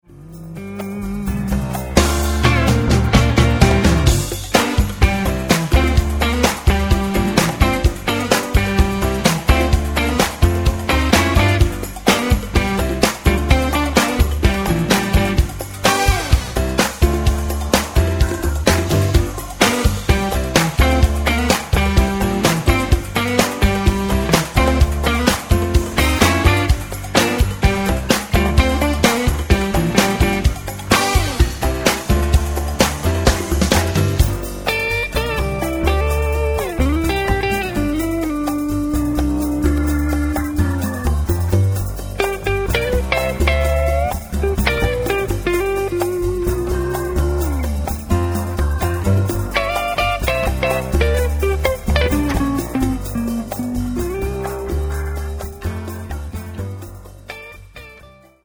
gitarzysta jazzowy